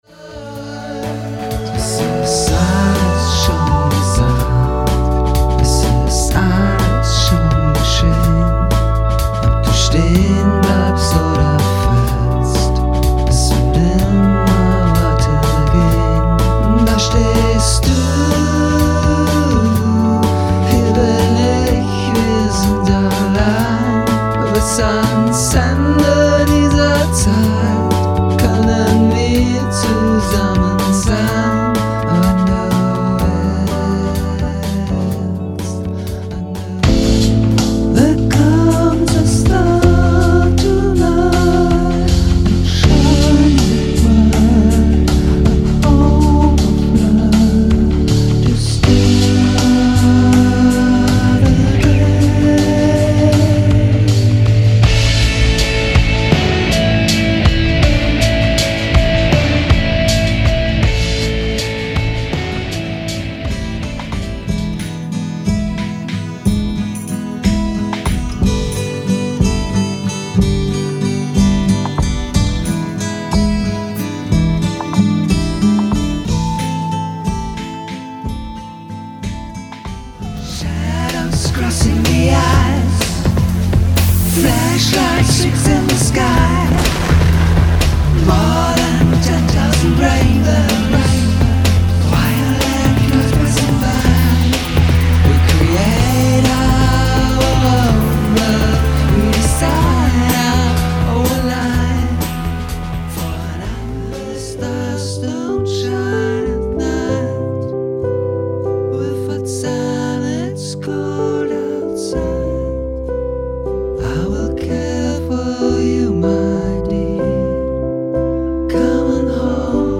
Recorded in Berlin